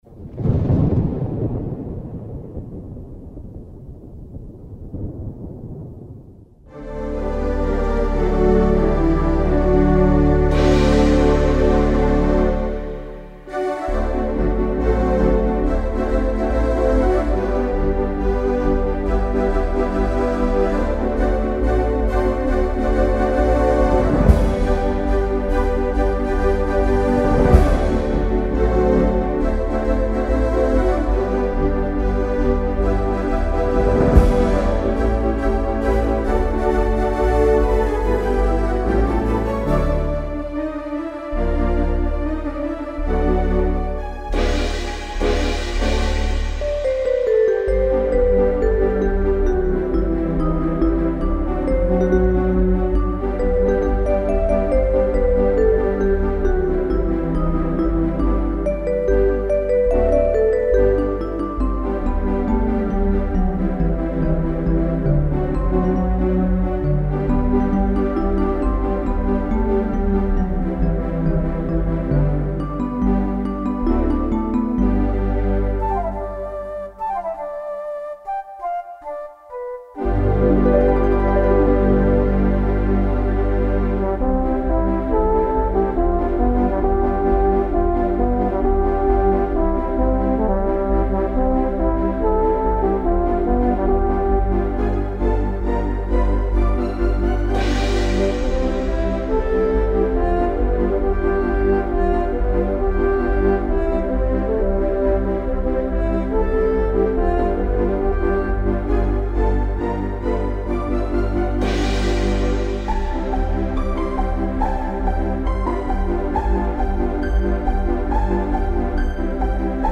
This enables any sound to be placed anywhere in the stereo soundfield from far left to far right.
So in this piece you will hear the strings, flute and english horn at the centre, the vibraphone and horns to the left and the trombone and brass section to the right.